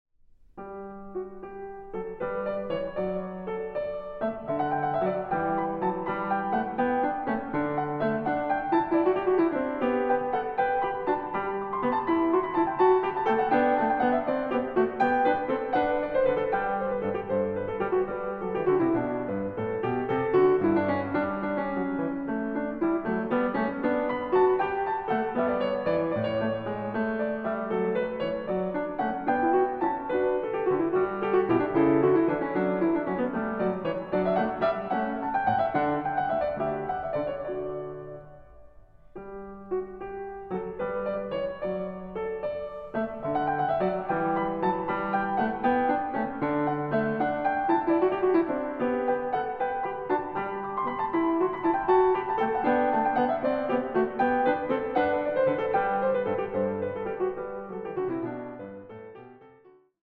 a 1 Clav.